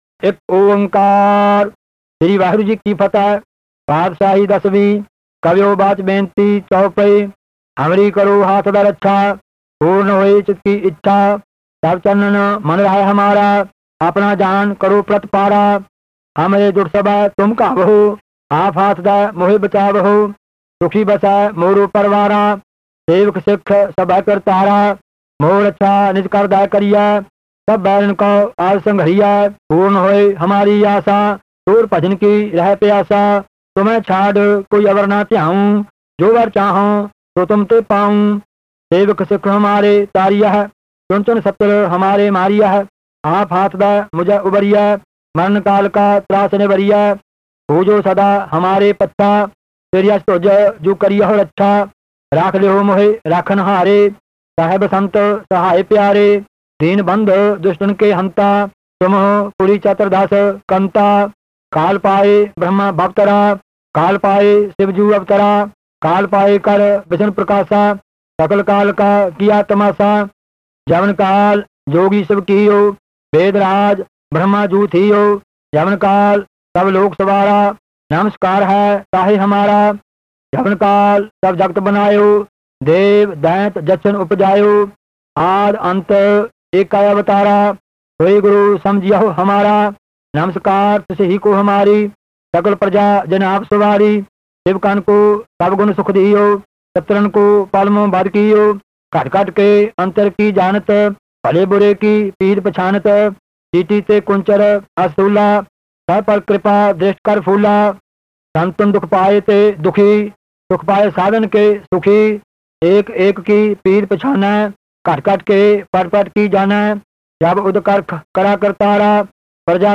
Genre: Gurbani Ucharan